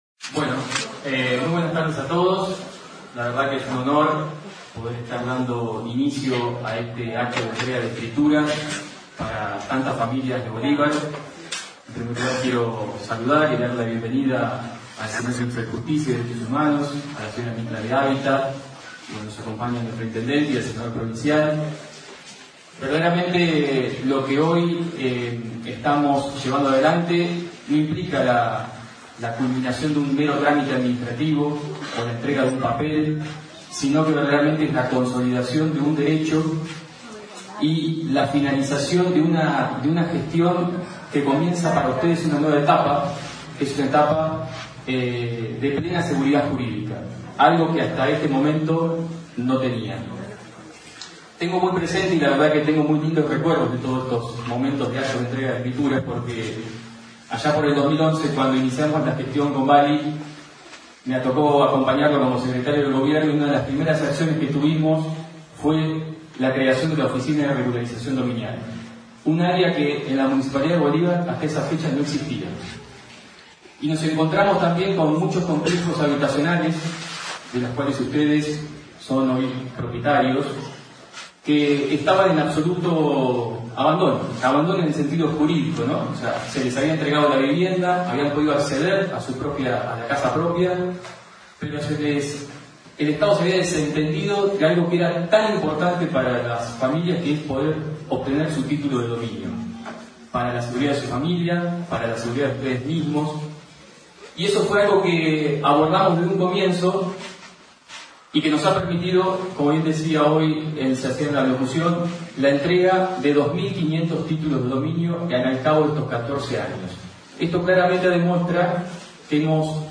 ACTO DE ENTREGA DE ESCRITURAS :: Radio Federal Bolívar